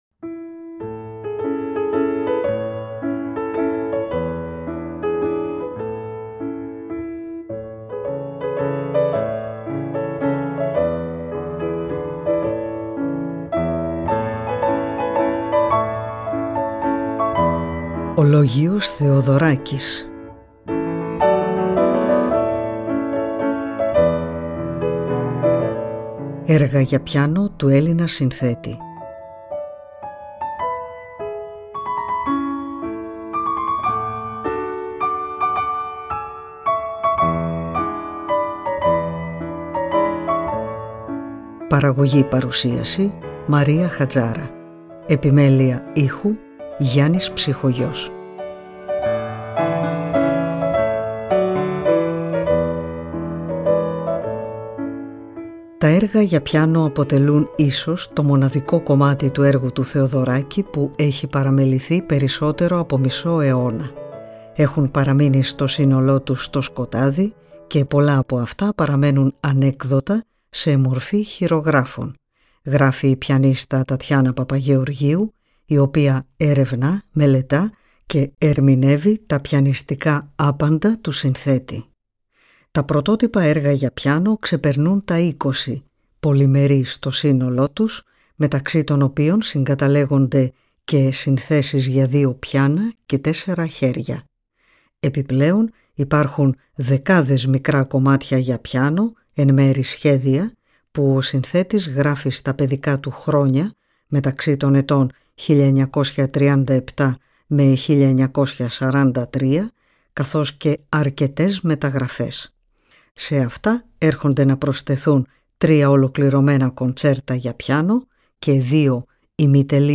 Έργα για πιάνο
για πιάνο